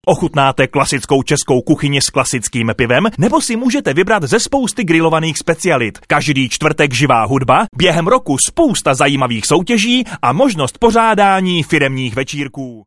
Sprecher tschechisch für TV / Rundfunk / Industrie Werbung.
Sprechprobe: Werbung (Muttersprache):
Professionell voice over artist from Czech.